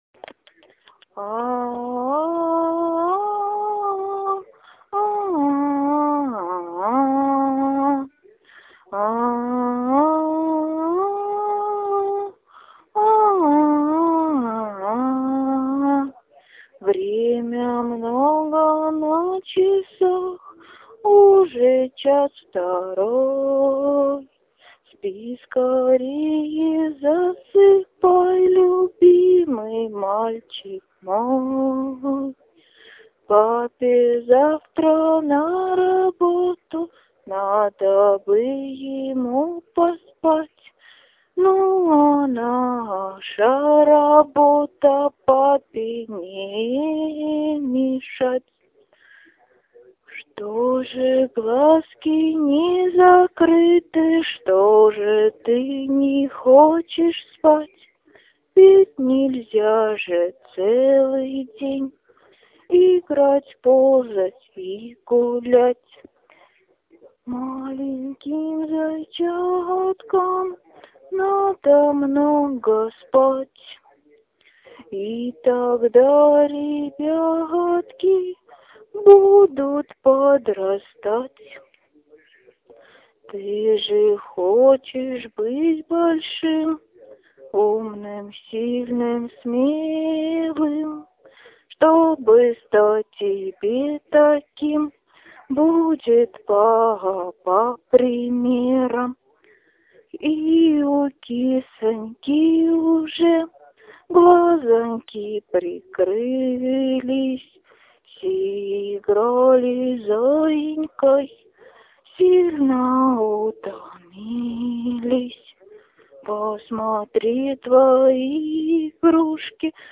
Необходимо сочинить колыбельную для своего малыша, переложить на музыку (сочиненную вами или любую известную фонограмму), записать ее в вашем исполнении или исполнении мужа.
Колыбельная